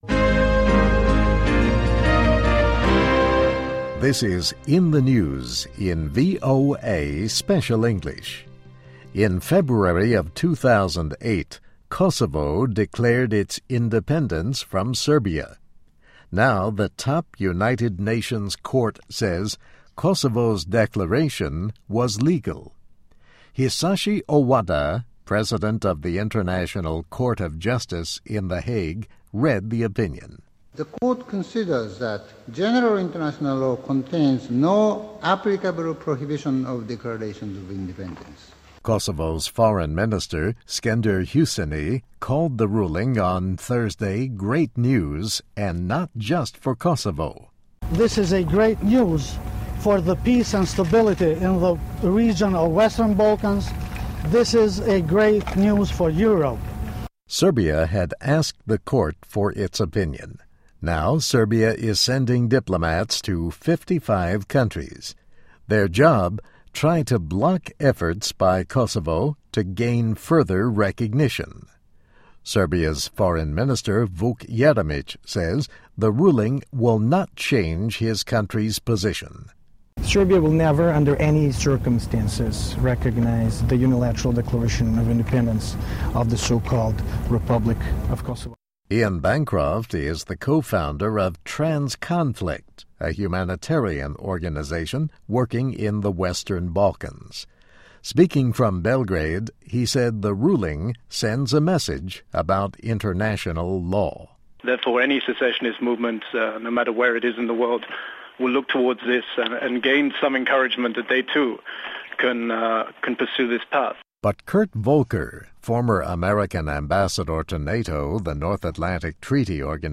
这里是VOA特别英语时事新闻